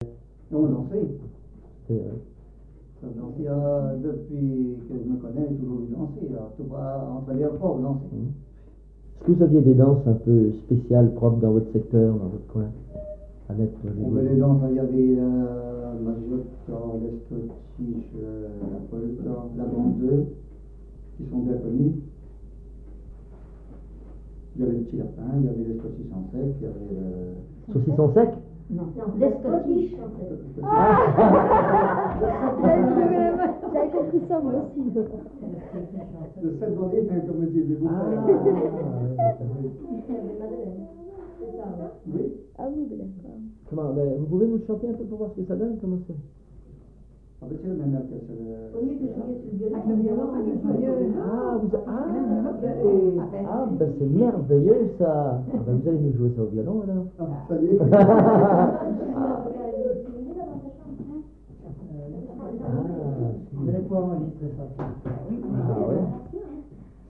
Enquête Puy du Fou, enquêtes des Puyfolais
Catégorie Témoignage